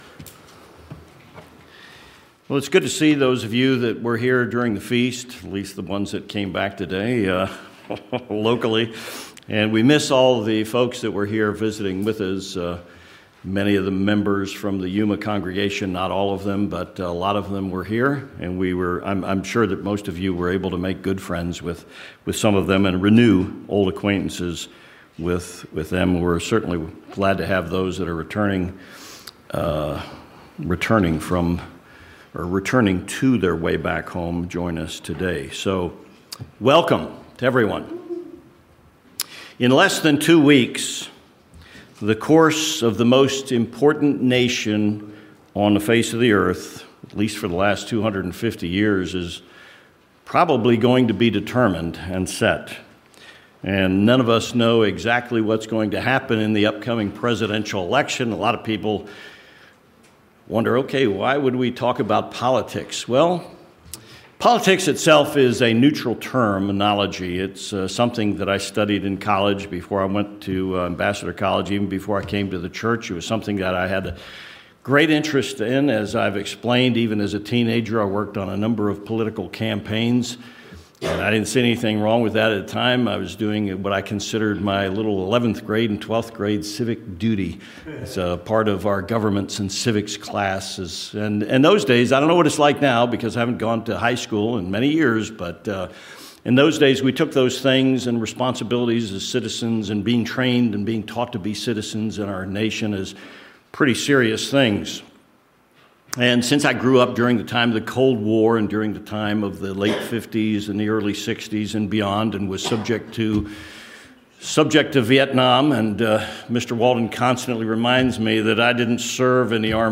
What will happen when America goes to the polls and selects a new President and votes on many issues in 2024? This sermon explains some possible scenarios.